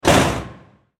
Звуки дверей поезда
Металлическая дверь резко захлопнулась